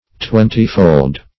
Twentyfold \Twen"ty*fold`\, a. Twenty times as many.